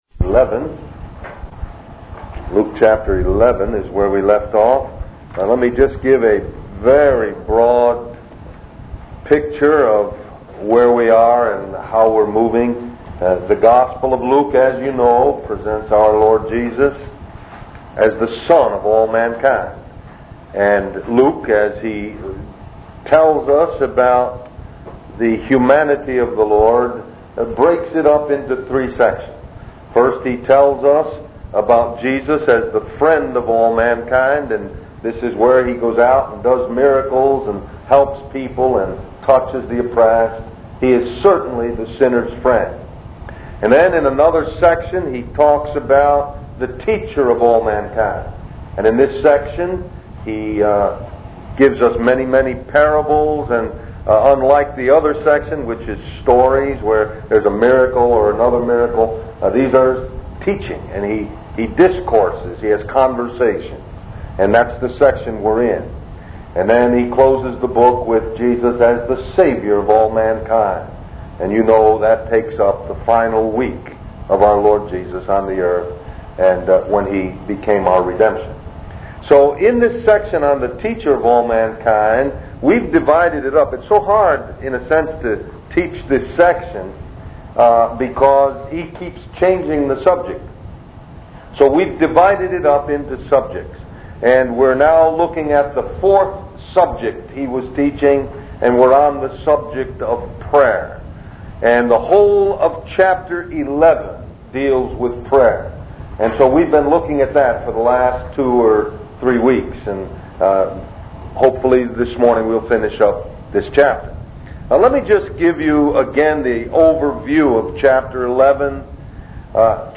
In this sermon, the speaker discusses the teaching style of Jesus and how he was able to seamlessly address different topics while keeping the main subject intact.